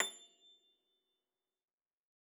53j-pno25-A5.wav